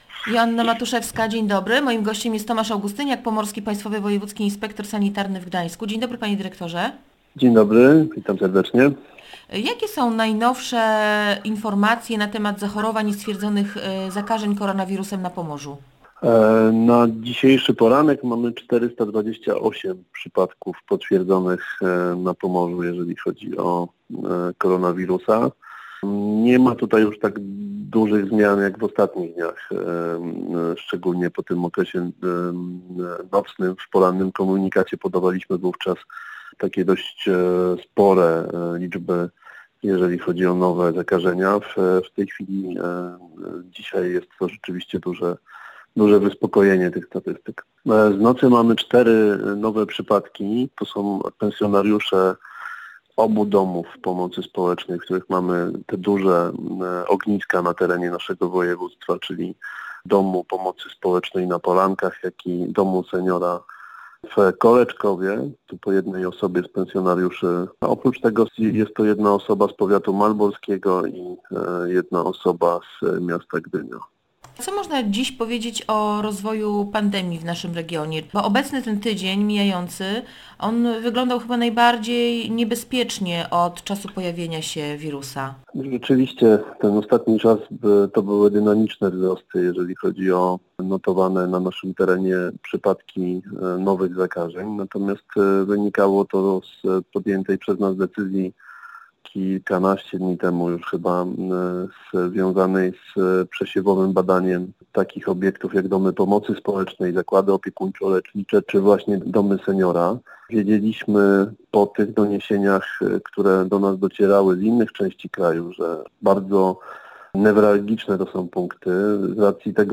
Prawidłowe noszenie maseczek ochronnych to dowód odpowiedzialności. Nie noście ich na czole, albo pod brodą – apelował w Radiu Gdańsk Tomasz Augustyniak, Pomorski Państwowy Wojewódzki Inspektor Sanitarny w Gdańsku.